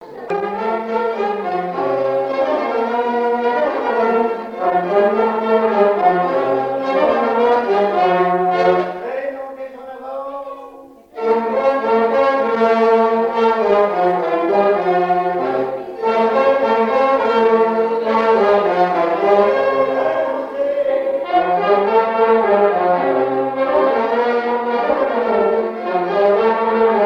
Quadrille - Chaîne anglaise
danse : quadrille : chaîne anglaise
Pièce musicale inédite